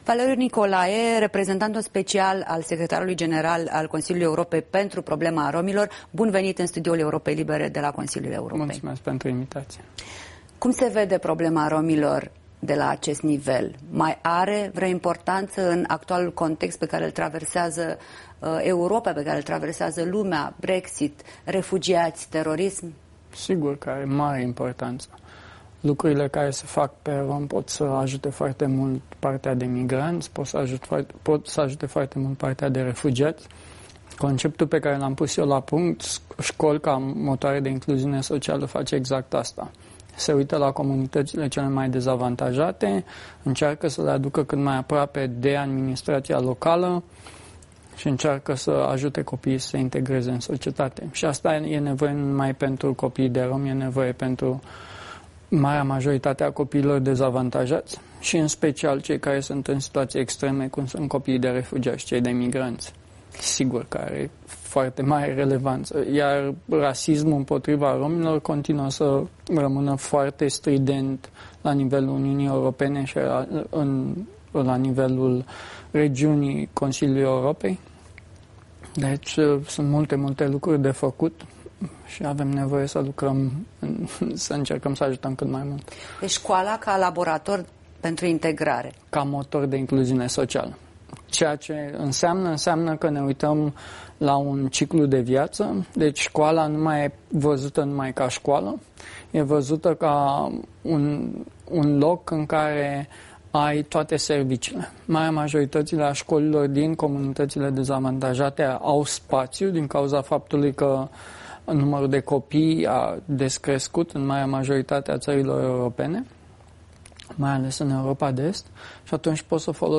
Un interviu cu reprezentantul Secretarului General al Consiliului Europei, Thorbjorn Jaglan, pentru problema romilor.